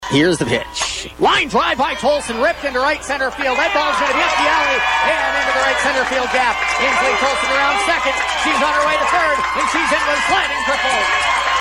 had the play-by-play call on Hot Country Z 101.7